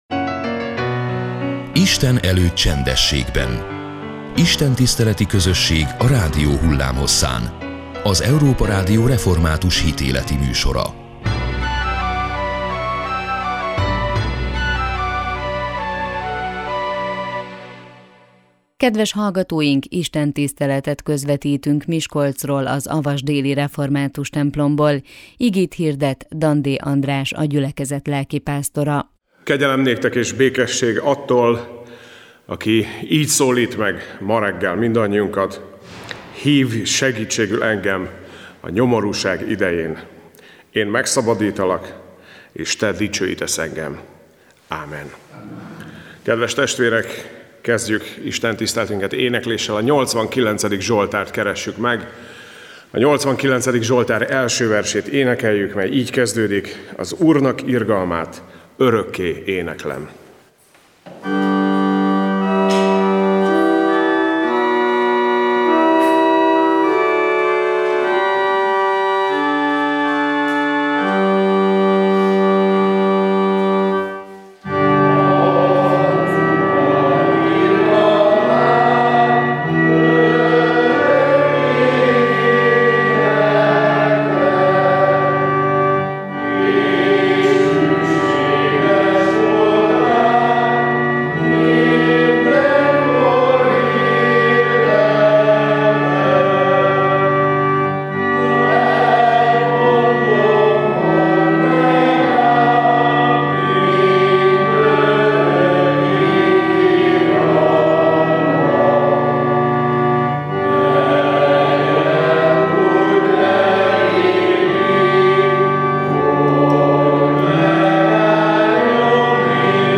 Istentiszteleti közösség a rádió hullámhosszán. Az Európa Rádió hitéleti műsora minden vasárnap és a református egyház ünnepnapjain.